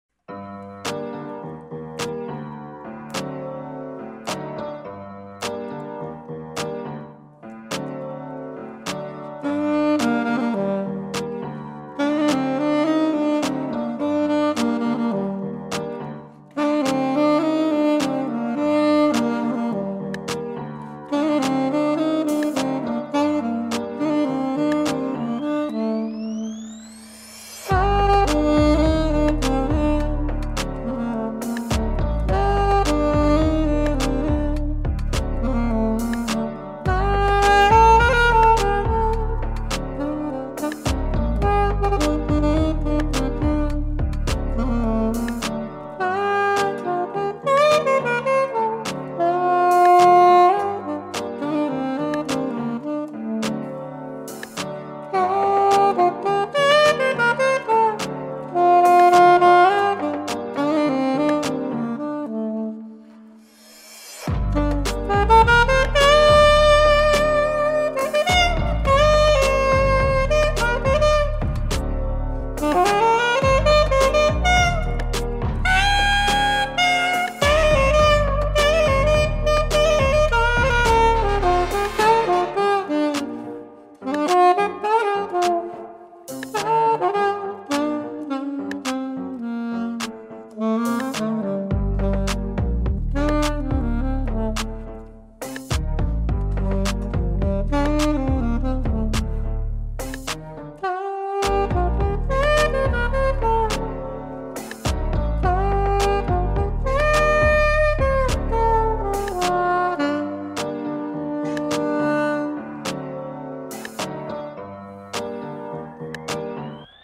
Saxophone Cover